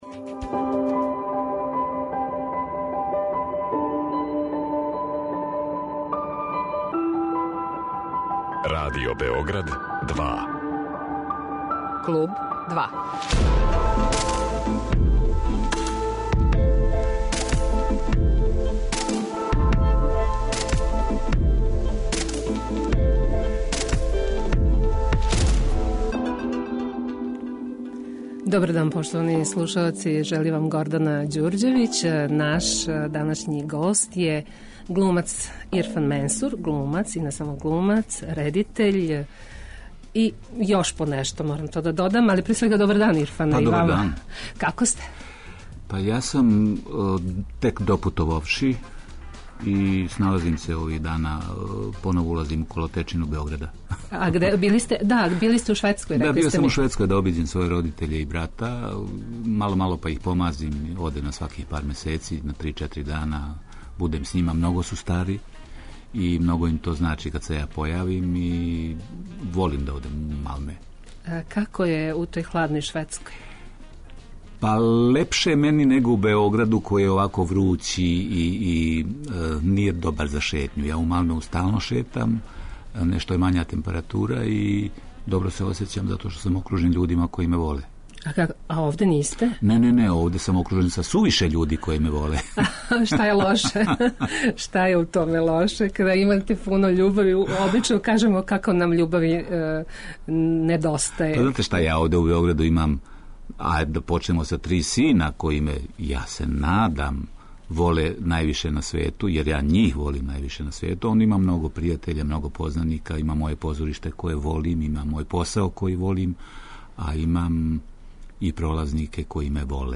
Гост 'Клубa 2' је Ирфан Менсур